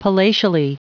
Prononciation du mot palatially en anglais (fichier audio)